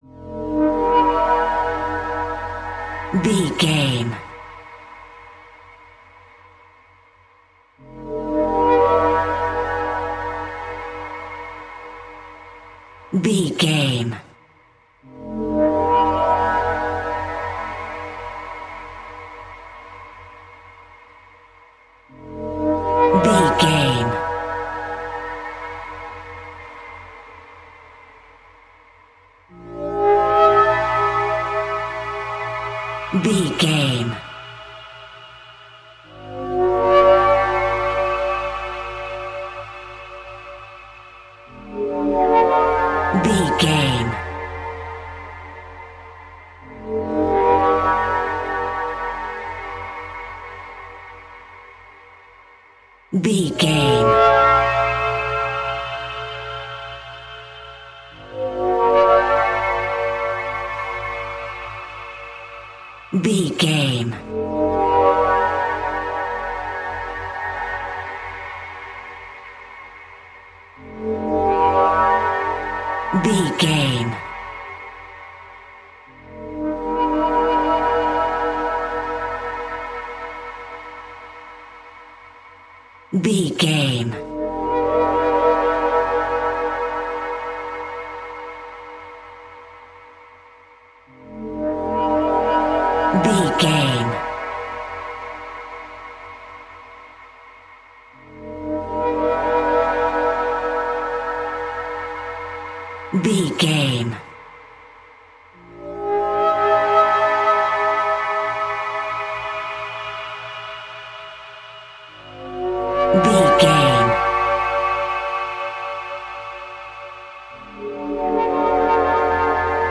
In-crescendo
Thriller
Atonal
E♭
ominous
eerie
ethereal
synthesiser
horror music
Horror Pads
Horror Synths